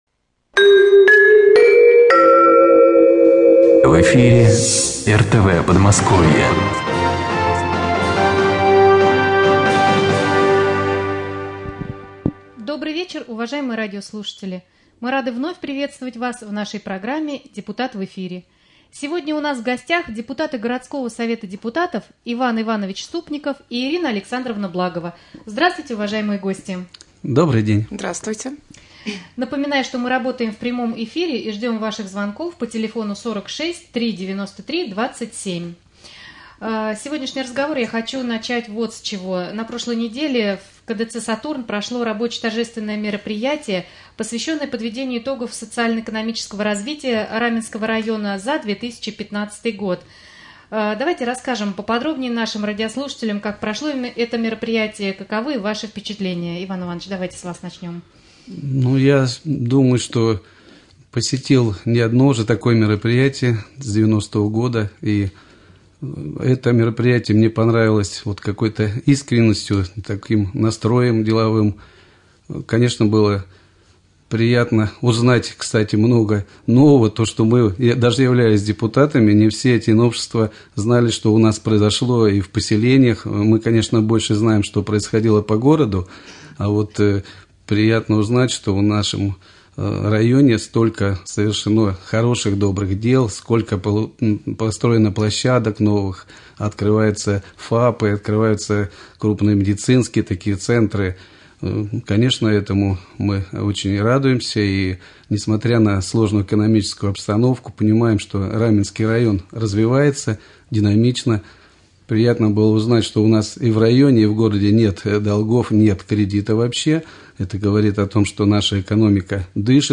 Прямой эфир с депутатами городского Совета депутатов Ириной Александровной Благовой и Иваном Ивановичем Ступниковым.